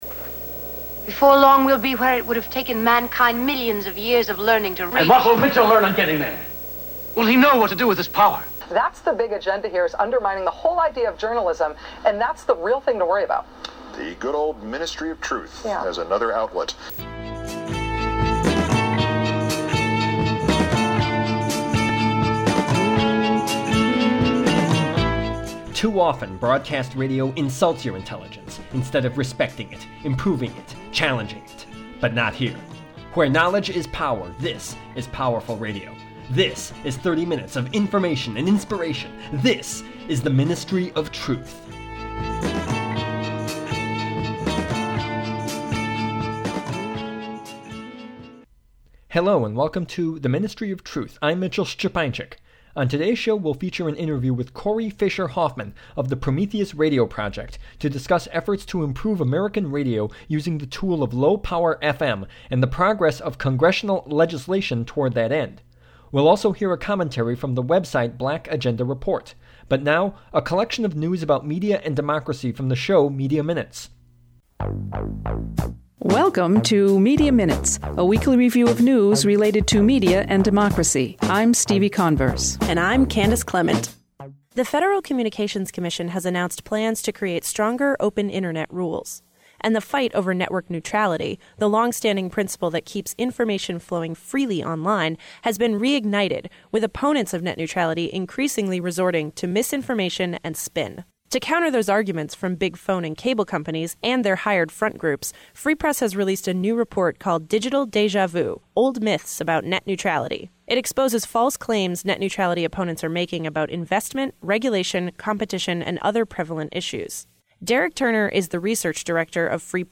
The Ministry of Truth: Interview